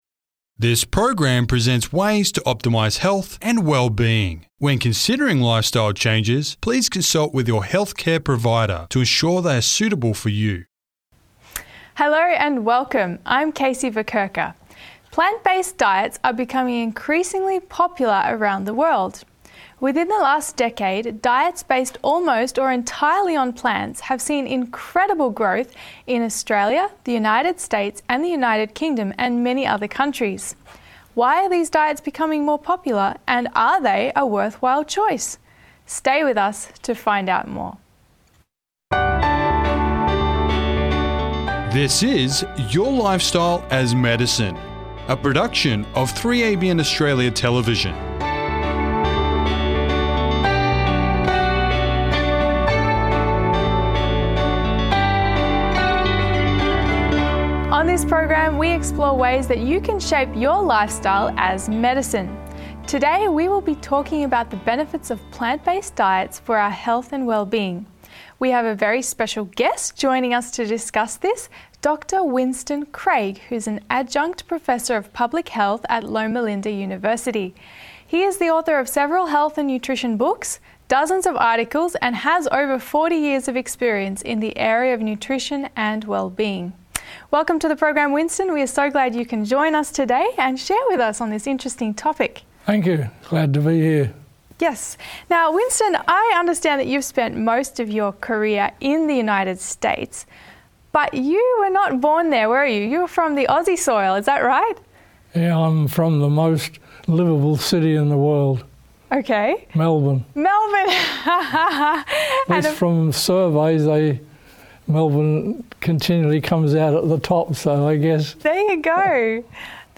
This program explores what plant-based diets are, their benefits, nutritional adequacy, and sustainability. Host: